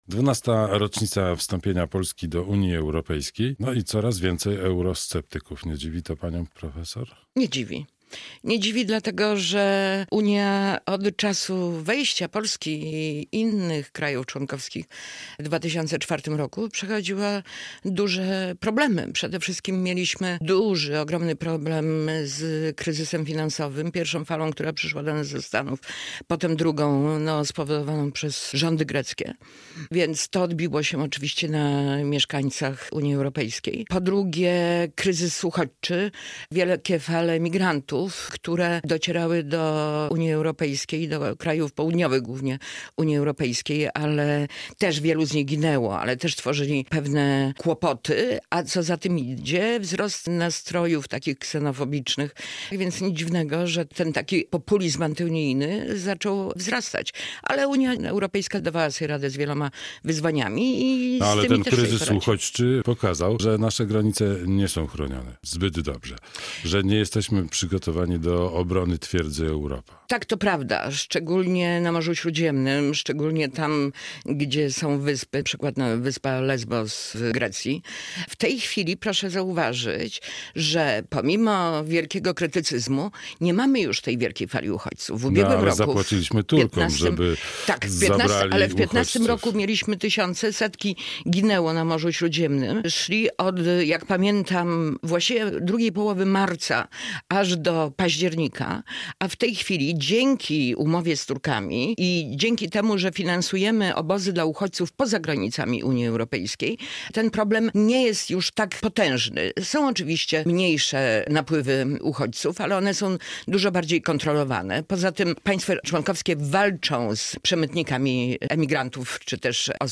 europosłanka PO
Studio Radia Bialystok